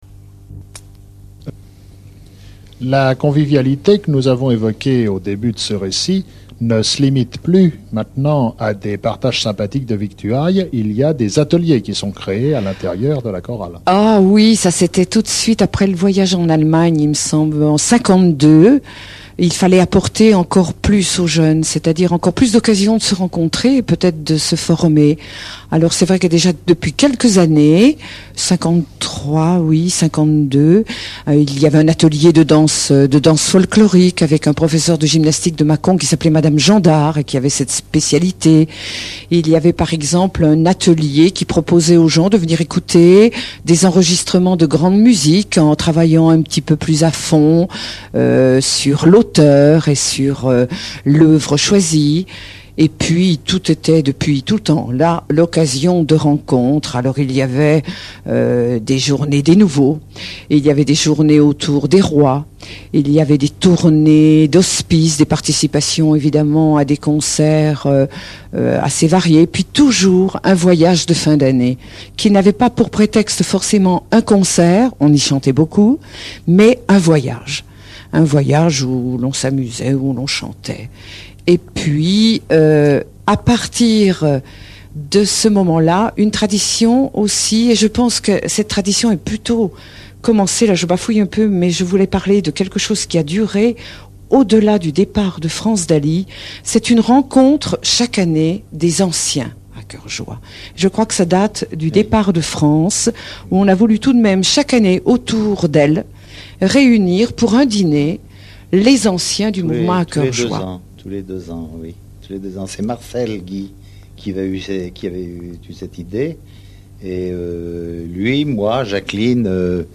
Interview Radio